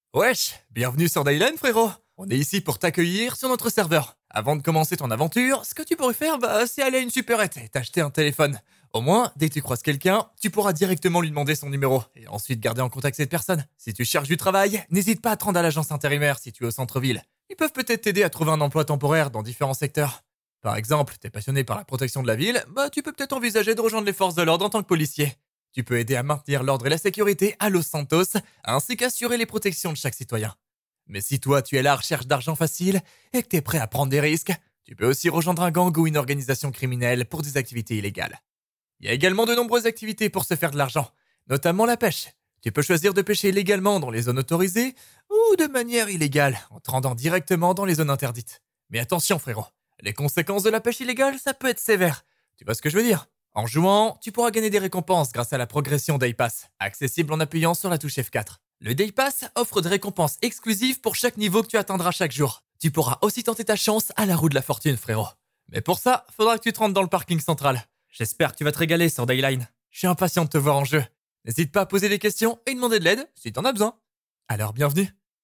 Voix off Dayline.mp3
voixoff-dayline.mp3